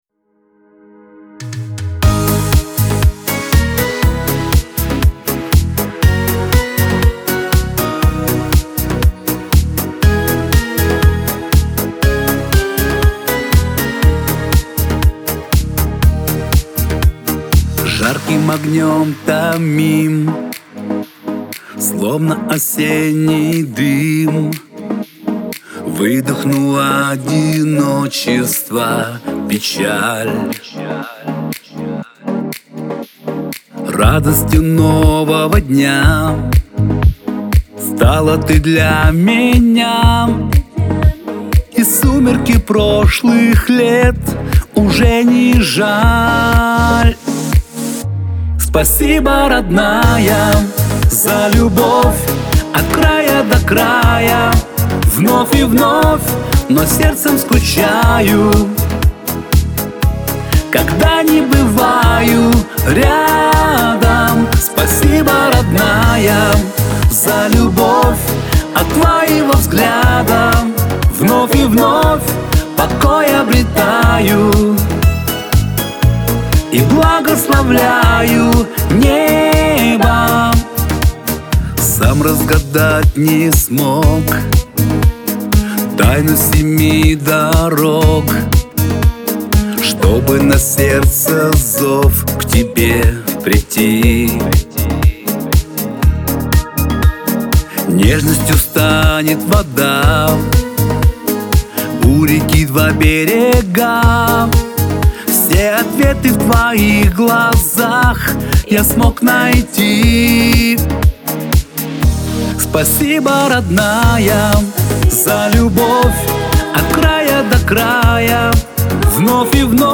pop
эстрада